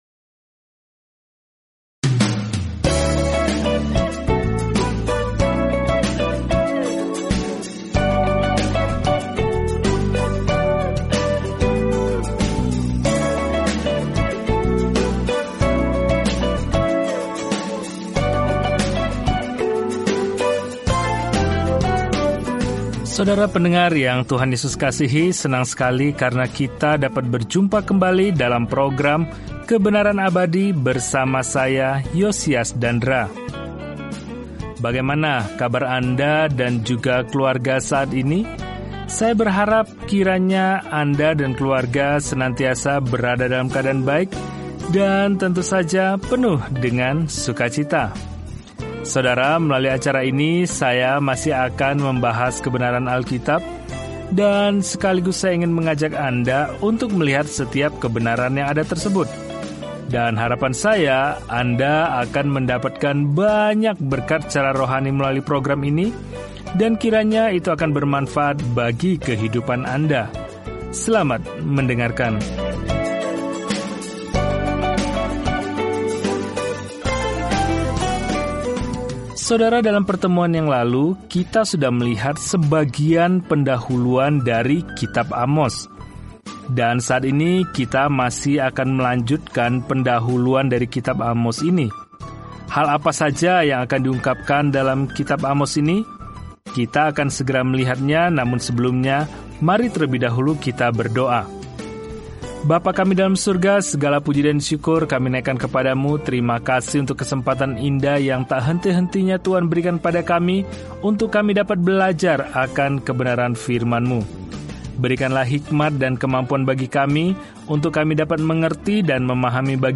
Firman Tuhan, Alkitab Amos 1:1-3 Hari 1 Mulai Rencana ini Hari 3 Tentang Rencana ini Amos, seorang pengkhotbah di pedesaan, pergi ke kota besar dan mengutuk cara-cara mereka yang penuh dosa, dengan mengatakan bahwa kita semua bertanggung jawab kepada Tuhan sesuai dengan terang yang Dia berikan kepada kita. Jelajahi Amos setiap hari sambil mendengarkan studi audio dan membaca ayat-ayat tertentu dari firman Tuhan.